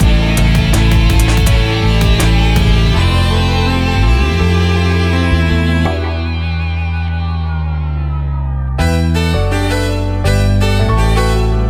最初２小節はドラムも鳴っていますが、その後リズムのよりどころを見つけにくいアレンジになっています。